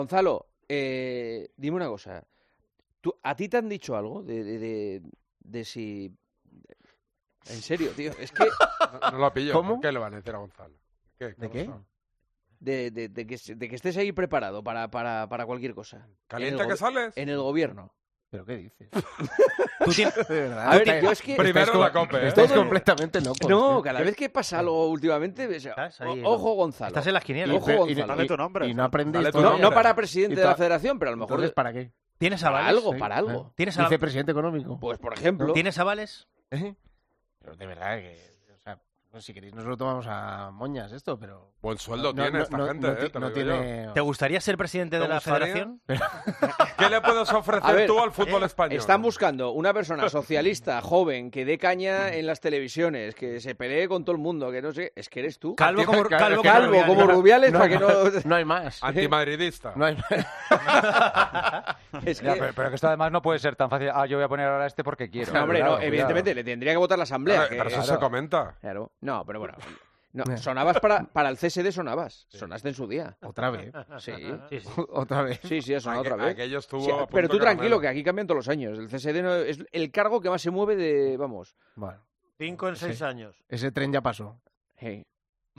El presentador de El Partidazo de COPE debatió con el tertuliano sobre la situación en la Federación y con Pedro Rocha